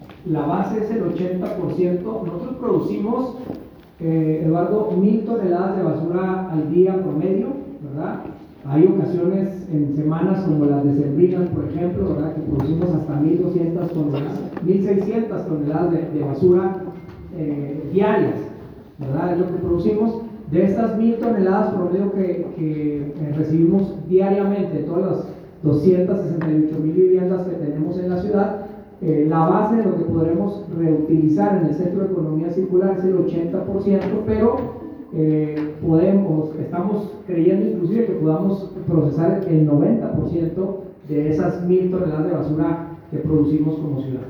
El alcalde capitalino Marco Antonio Bonilla Mendoza llevó a cabo una conferencia de prensa en que hizo públicos algunos detalles del futuro Complejo Ambiental del Municipio de Chihuahua, que consta de una planta tratadora de residuos sólidos que procesará alrededor de 1 mil 440 toneladas de basura diaria y no generará olores, ni contaminación del aire.